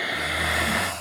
HippoSnores-002.wav